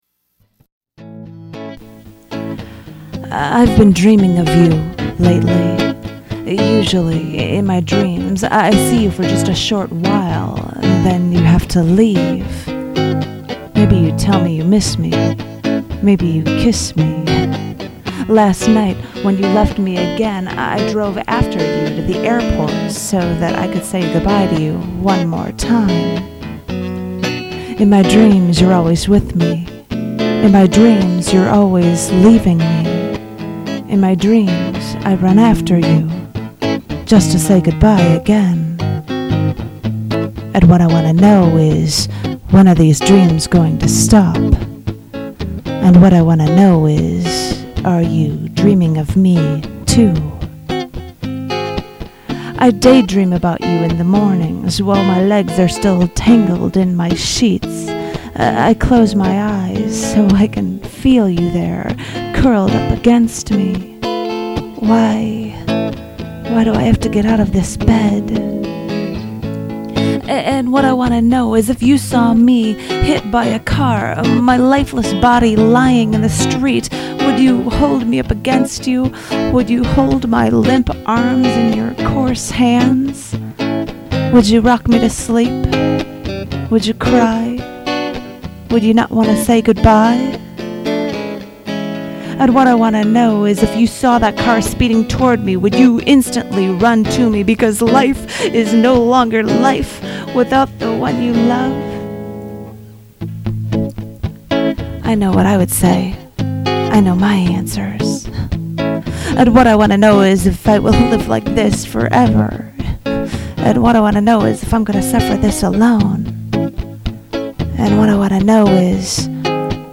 designed for the Chicago performance art event
(LIVE track, recorded 04/01/5)
read in the feature art gallery performance art show
• Boss DR-550mkII drum box
• Vox AD15VT guitar amplifier
• Fender American Telecaster